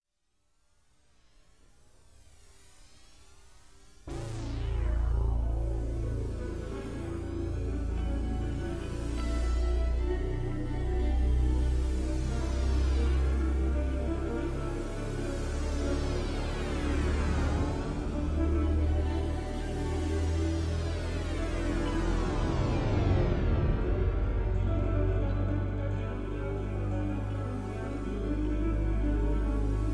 Comedy Tune about Being Groovy.
Background music suitable for TV/Film use.